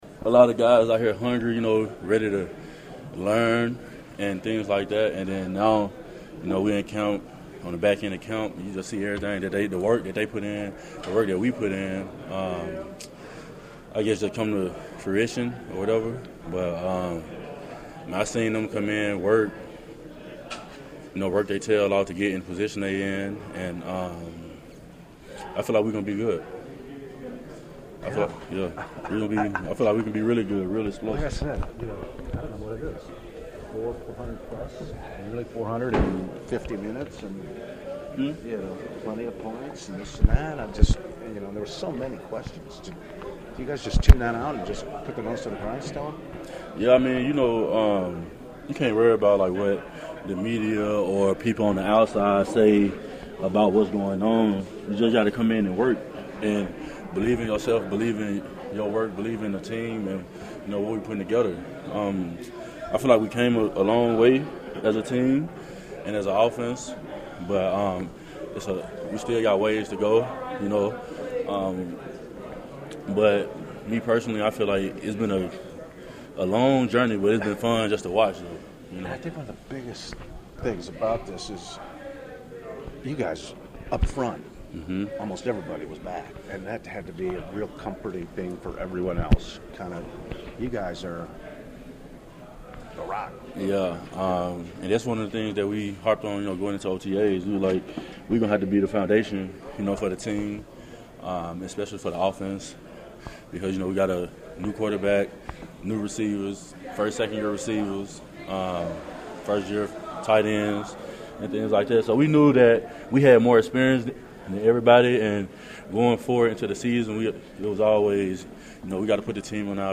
It was a question I posed to Pro Bowl left guard Elgton Jenkins.    According to Elgton, it’s come along just fine and the veteran players up front are ready to lead the unit into the regular season.